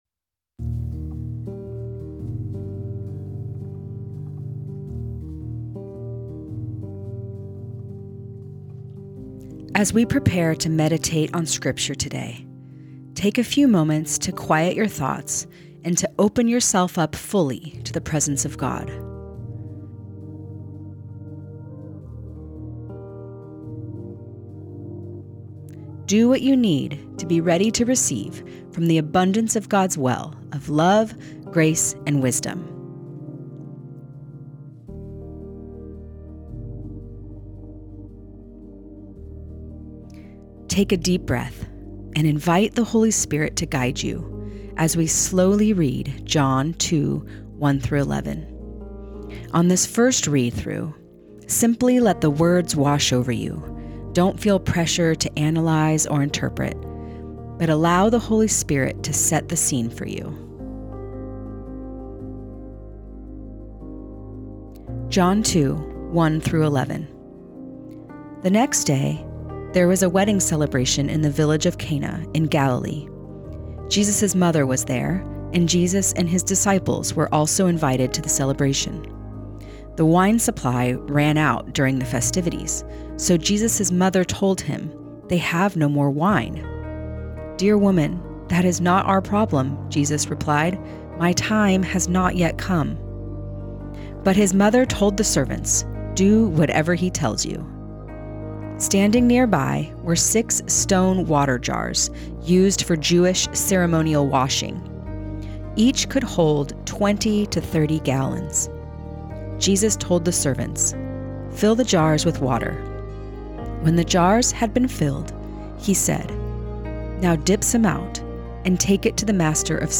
Guided Listening Practice Read John 2:1-11 The next day there was a wedding celebration in the village of Cana in Galilee.